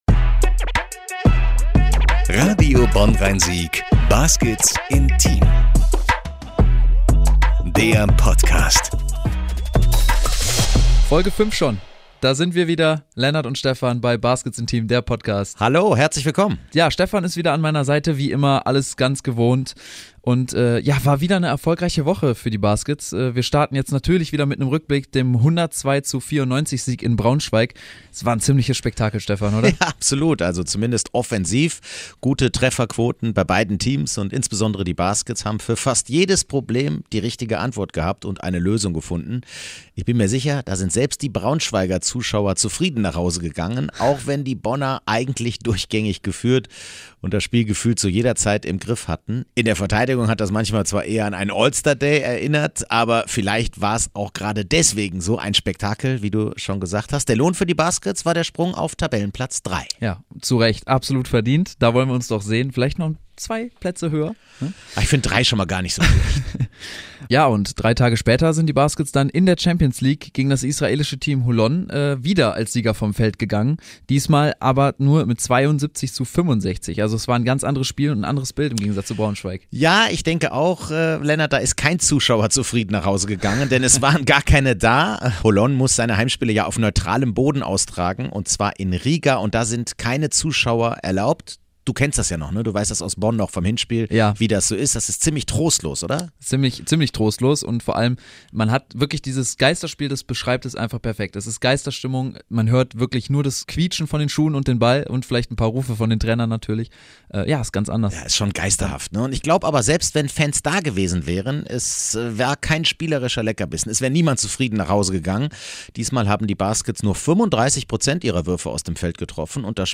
ihren ersten Gast ins Studio eingeladen
Interview